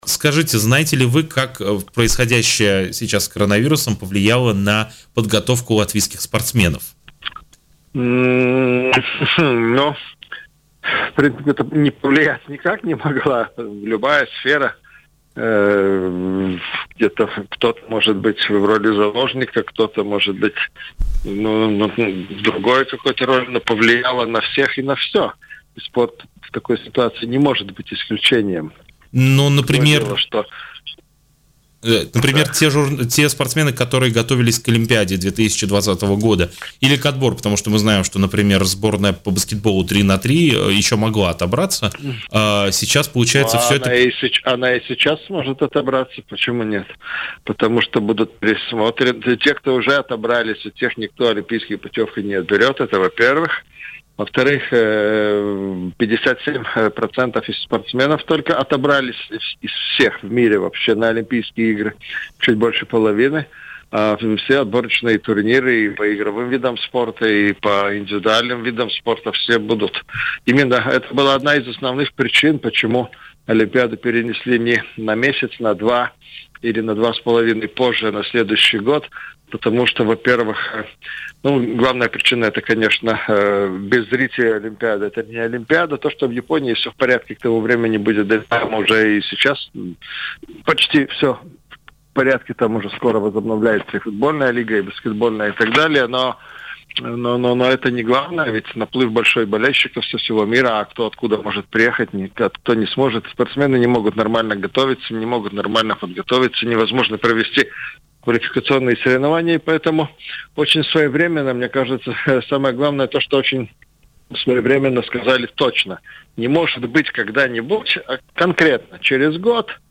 Из-за кризиса, вызванного пандемией коронавируса, пострадал практически весь латвийский спорт. Однако некоторые дисциплины смогут пережить этот период с наименьшими потерями. Такое мнение в эфире радио Baltkom высказал спортивный журналист